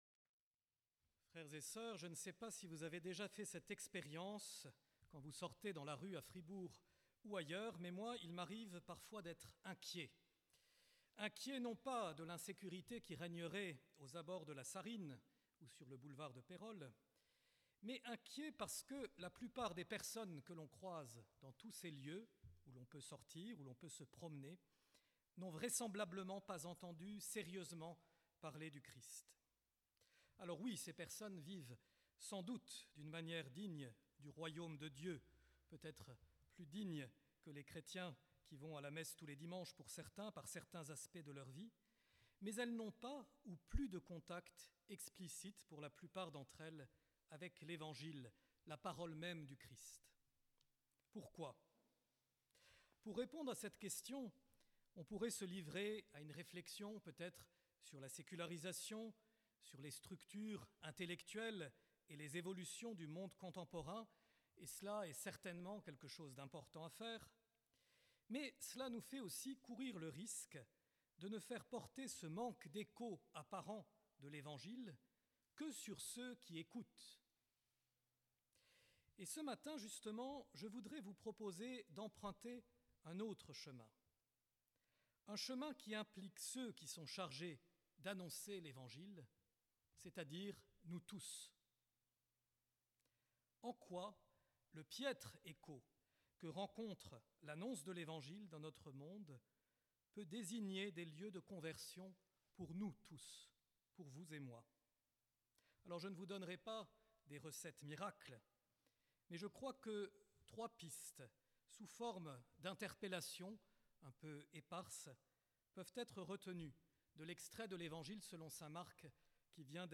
Pour découvrir son homllie, nous vous invitons à écouter un enregistrement en direct.